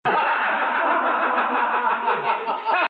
risas